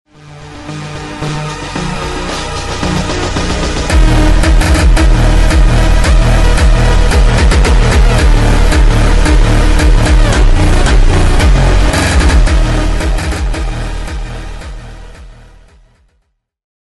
P 51 Mustang , F 22 Raptor Sound Effects Free Download